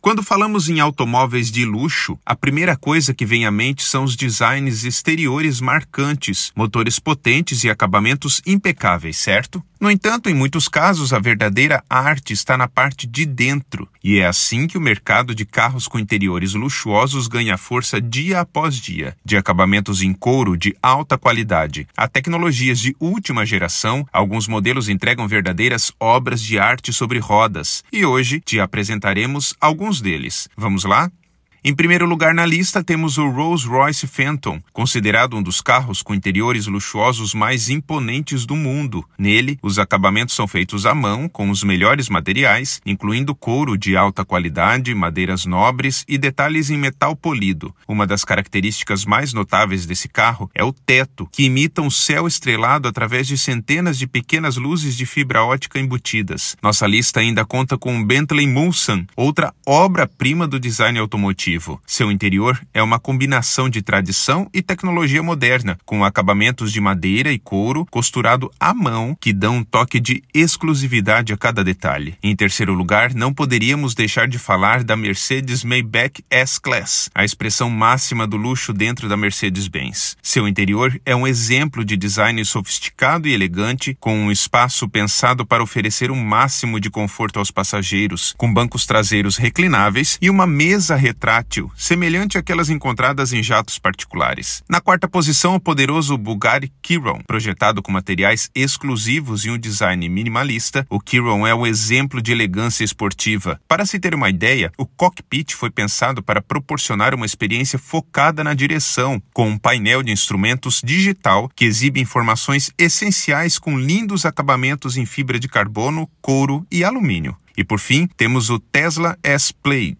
Narracao-02-carros-com-interiores-luxuosos.mp3